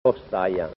• tra due vocali indica la semivocale intensa /jj/:
ôstaja" /ɔ:'stajja/ (osteria)